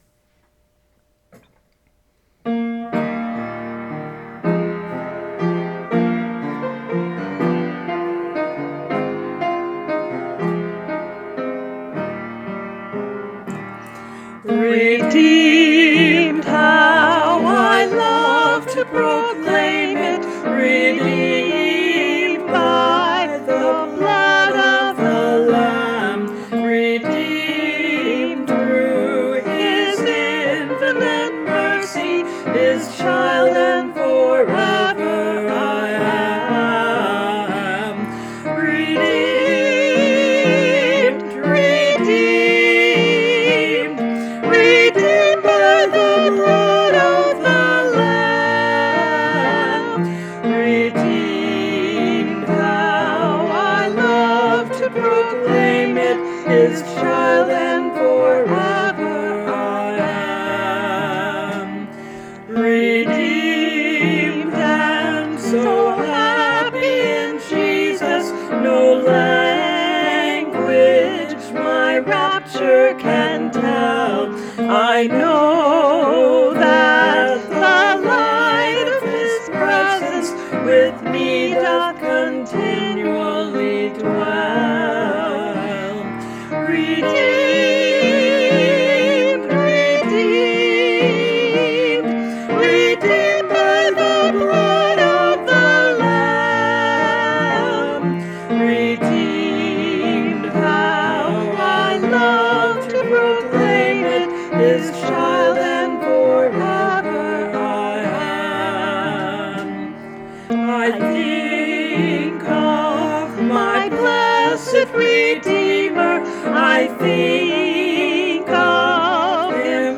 Then I told her to add an alto part.
As part of my practicing, I recorded my part as well so that I could share it with you.
Keyboard